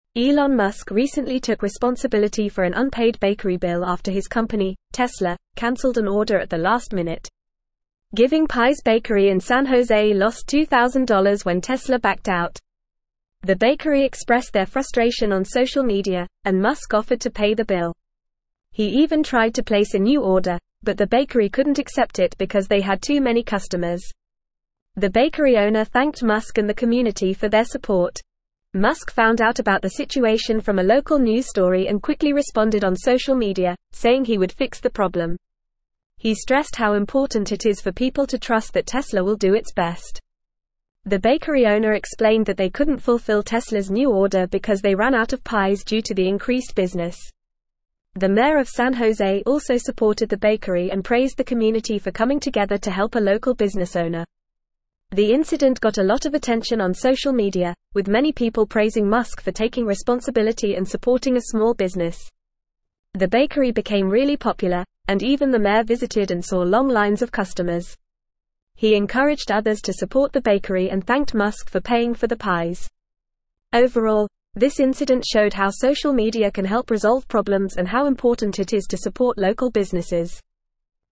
Fast
English-Newsroom-Upper-Intermediate-FAST-Reading-Elon-Musk-Saves-Bakery-Receives-Overwhelming-Community-Support.mp3